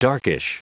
Prononciation du mot : darkish